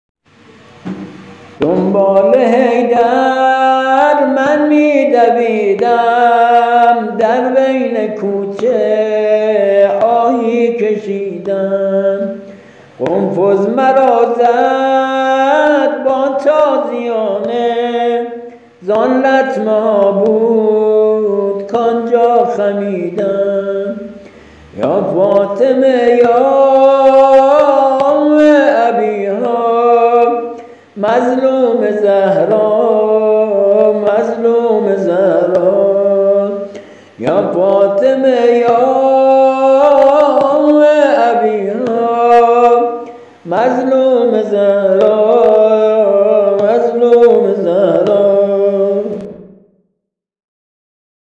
زمزمه و زمینه ایام فاطمیه
◾روضه‌ی فاطمیه